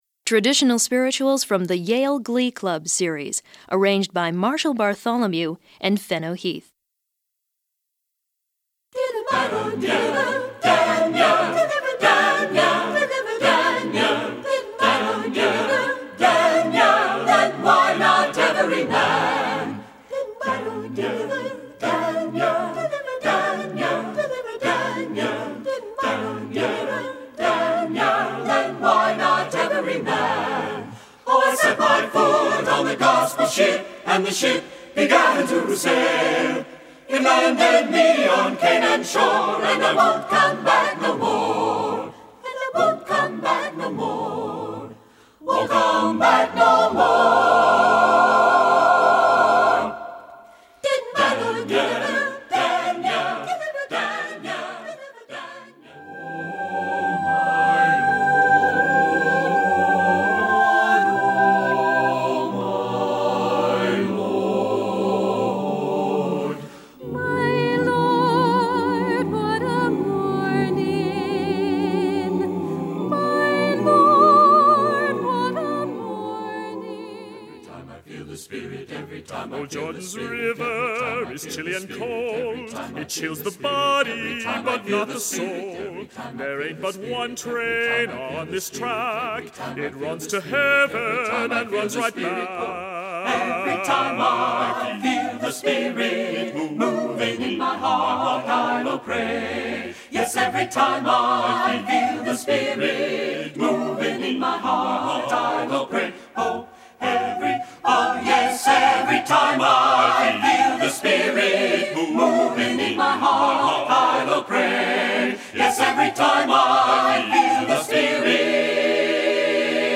Available TTBB and SATB.
SATB